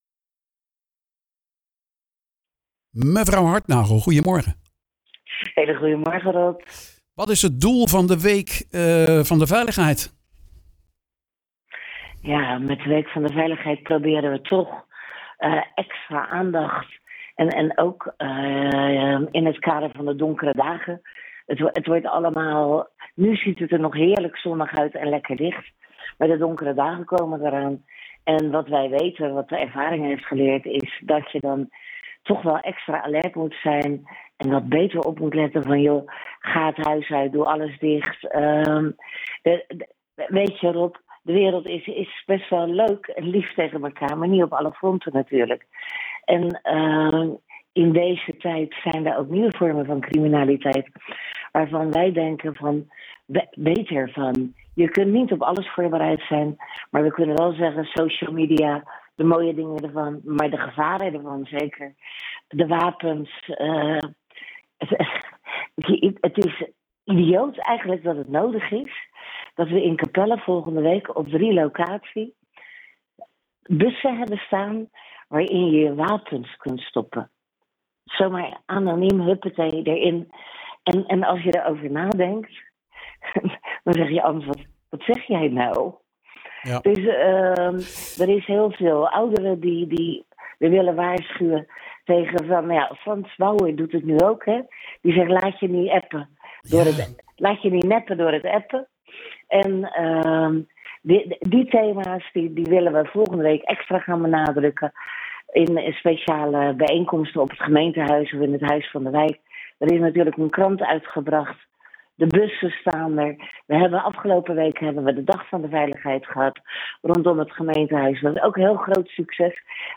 praat erover met wethouder veiligheid Ans Hartnagel